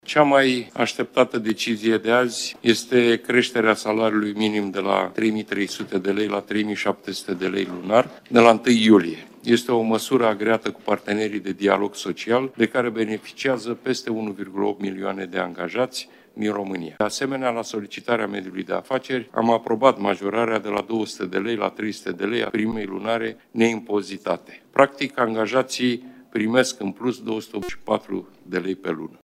Premierul Marcel Ciolacu a spus, la începutul ședinței de Guvern, că 300 de lei din salariul minim vor fi scutiți de impozite, față de 200 de lei cât era până acum